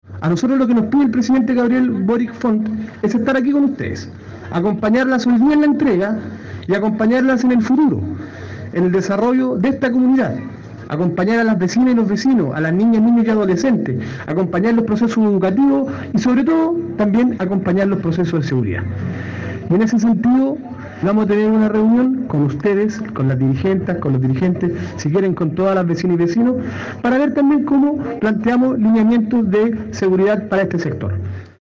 En un maravilloso día de sol, 293 familias curicanas recibieron las escrituras de sus viviendas sociales, documento que las acredita legalmente como propietarias de sus hogares.